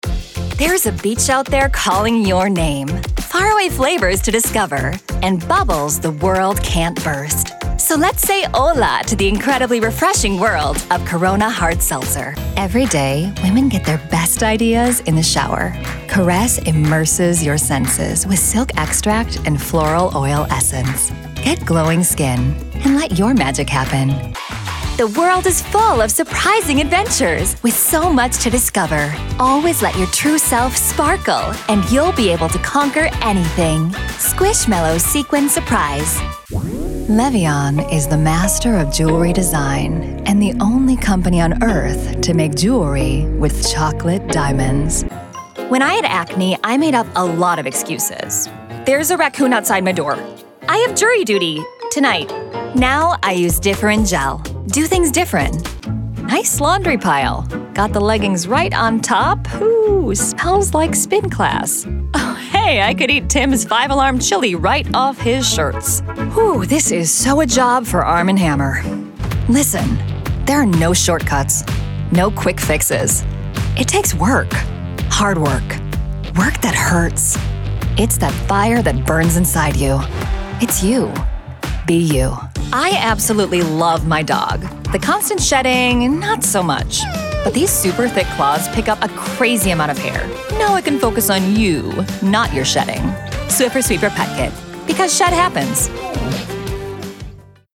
Trilingual VO pro with a warm, personable, sunny, trustworthy, friendly, and fun vibe!
Commercial Demo
I can regularly be found in my broadcast-quality home studio (equipped with Source Connect, phone patch, video call, a Shure SM7B microphone, and a Scarlett 2i2 interface) recording TV and radio commercials, scripted podcasts, internal corporate videos, IVRs, and children's audiobooks, often for repeat clients.
My voice has a warm, personable, sunny, trustworthy, friendly, fresh, and fun quality.